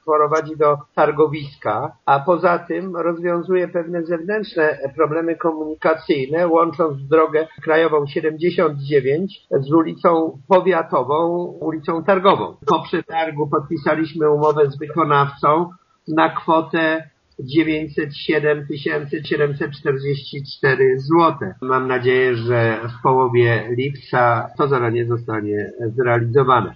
Zastępca burmistrza Włodzimierz Kabus podkreśla, że to bardzo ważna inwestycja, ponieważ dotyczy jednej ze strategicznych ulic w mieście: